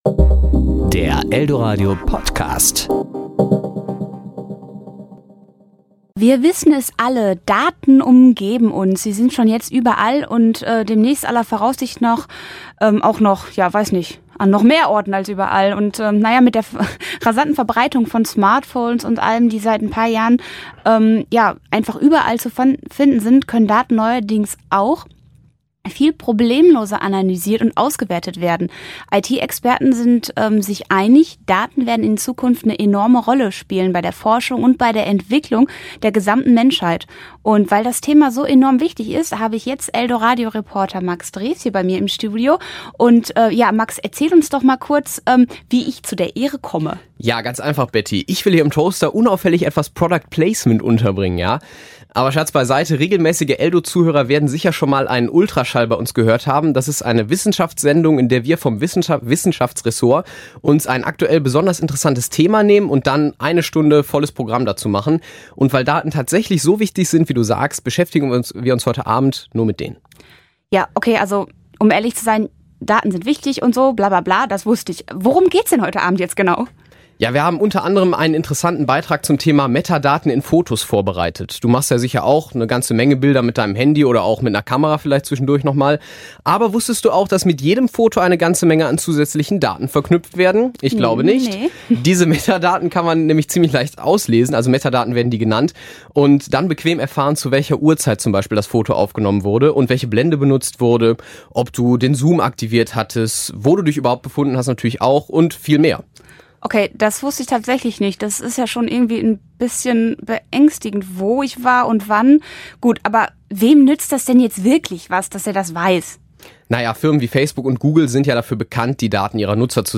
Serie: Kollegengespräch